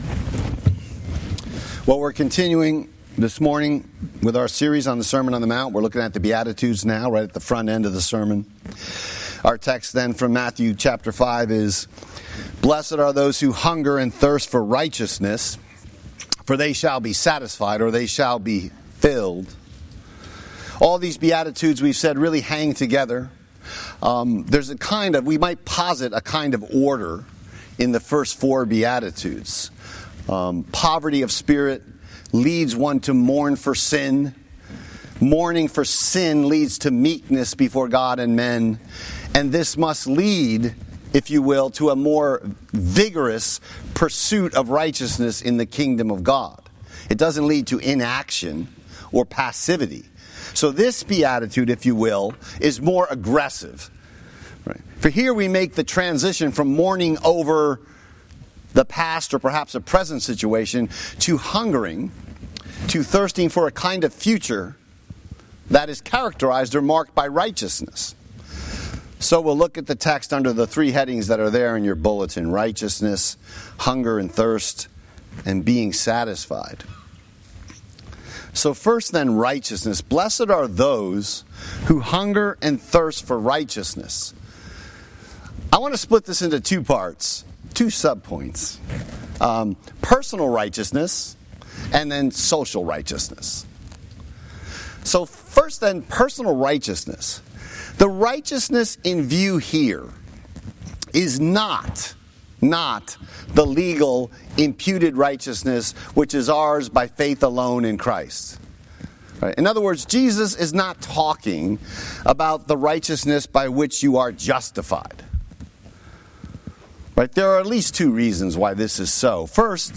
Sermon Text: Matthew 5:1-12